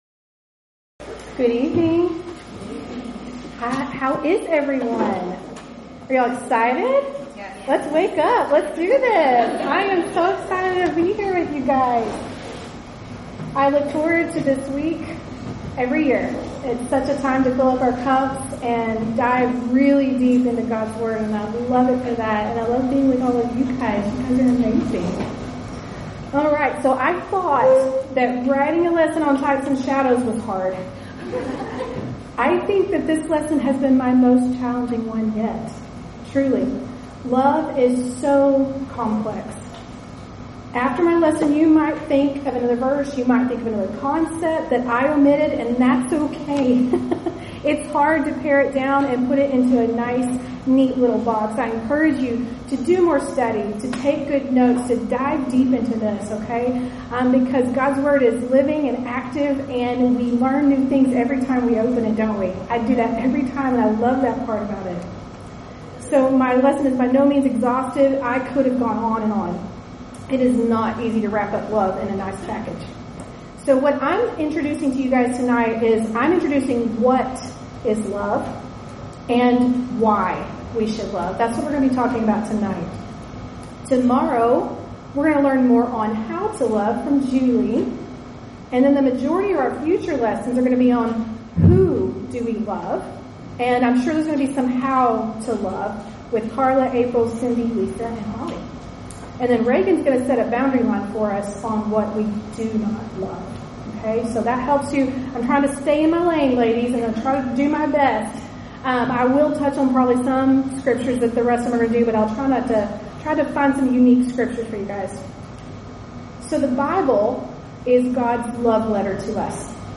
Event: 8th Annual Women of Valor Ladies Retreat
Ladies Sessions